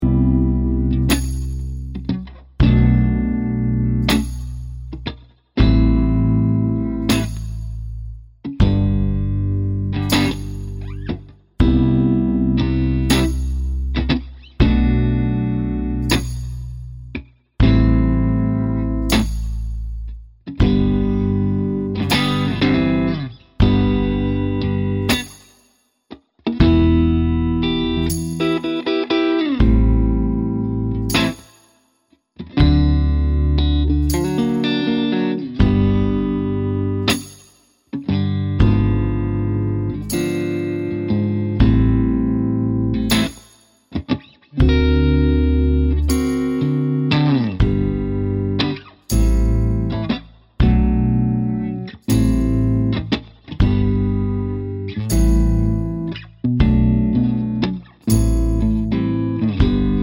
Minus Main Guitar Pop (2010s) 3:44 Buy £1.50